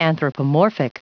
Prononciation du mot anthropomorphic en anglais (fichier audio)